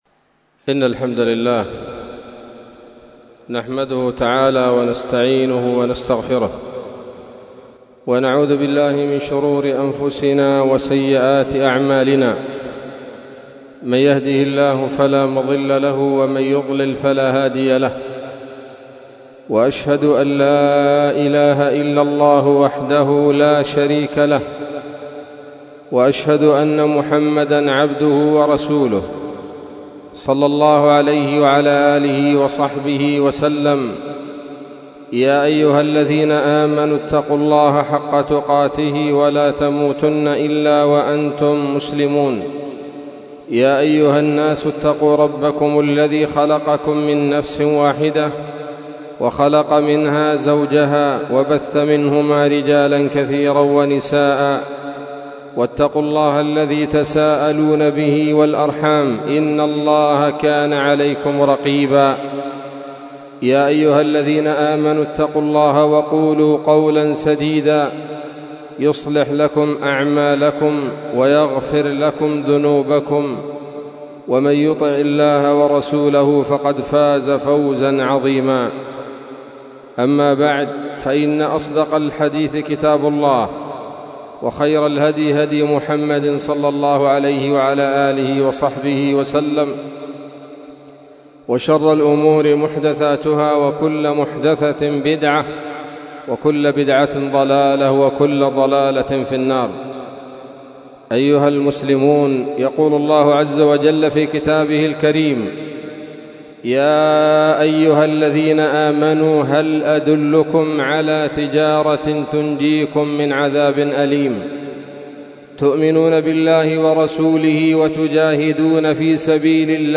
خطبة جمعة بعنوان: (( الجهاد في سبيل الله تجارة مع الله )) 4 جمادى الآخرة 1443 هـ، دار الحديث السلفية بصلاح الدين